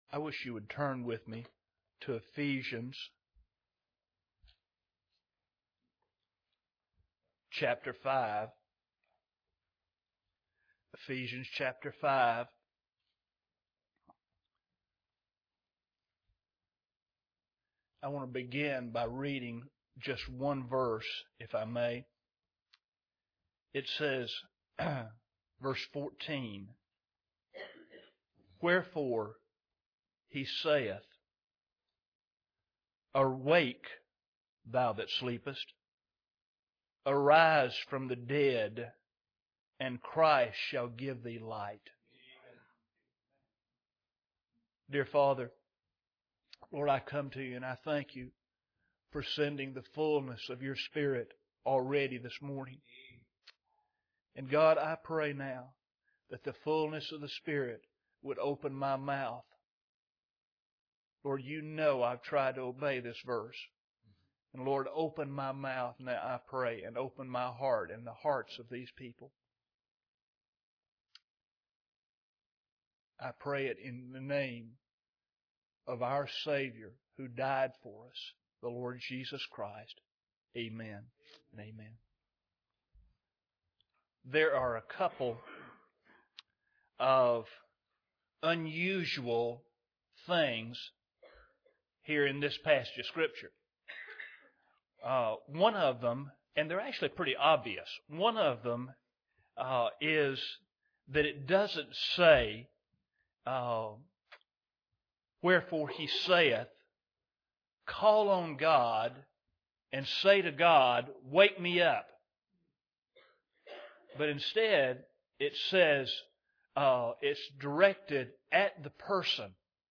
This sermon focuses on Paul’s call for the Ephesian Christians to awake from their spiritual slumber and receive the light of Christ.
Service Type: Sunday Morning